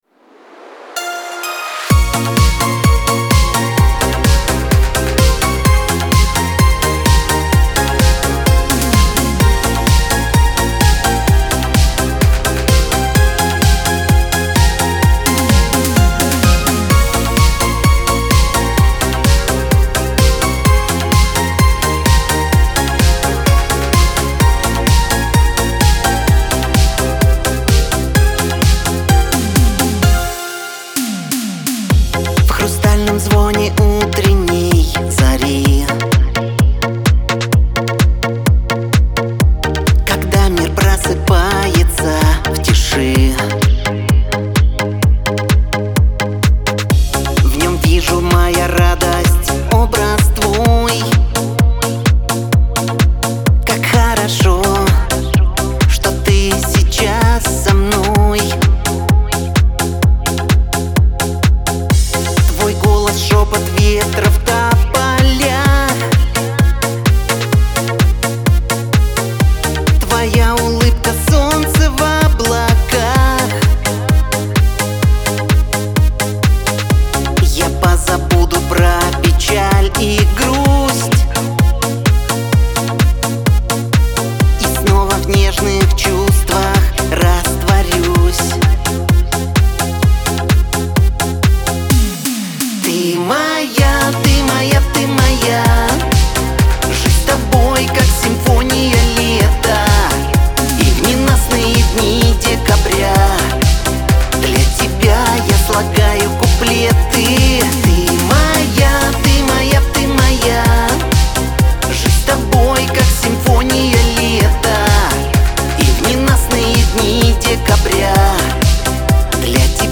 эстрада
dance
pop , диско